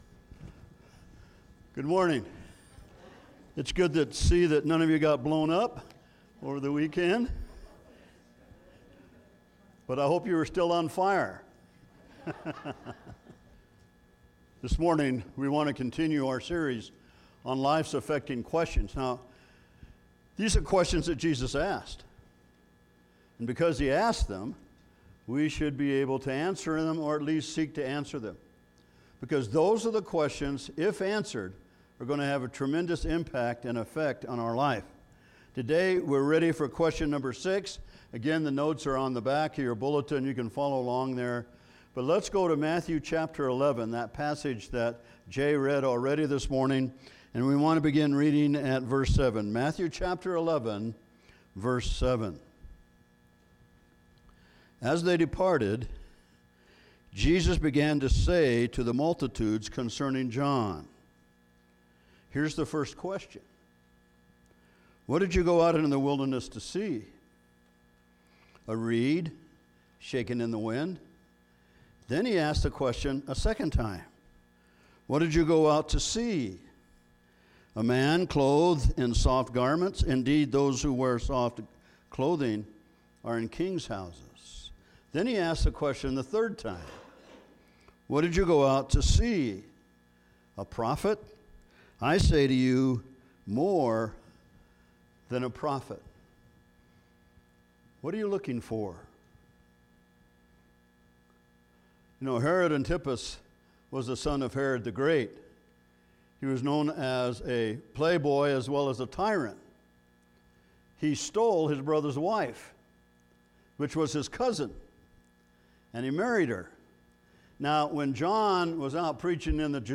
Weekly Sermons - Evangelical Free Church of Windsor, CO
Subscribe » Be A Rebel: Follow Jesus, Together - Growing Up Posted on April 14, 2024 Text: Romans 12-15 (Forgive certain technical difficulties, we should have it handled on the next service. If you note random music popping in, it is to inform the listener a gap in time has taken...